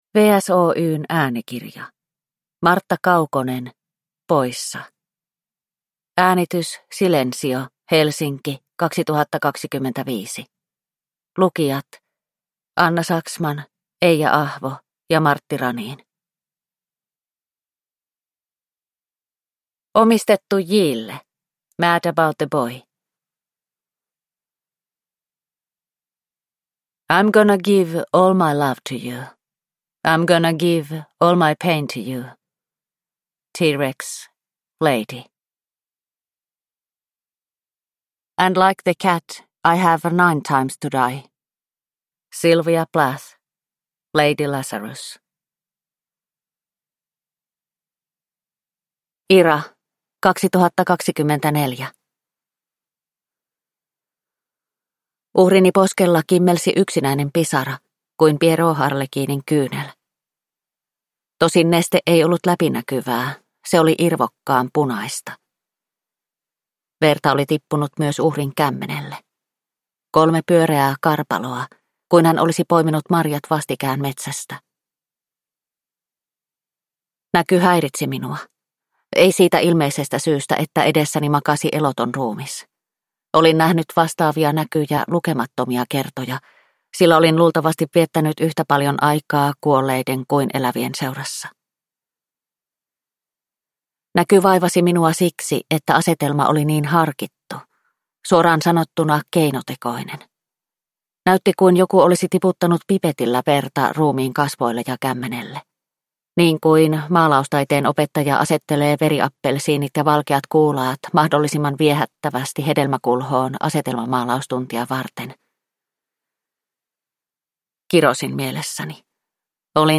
Poissa – Ljudbok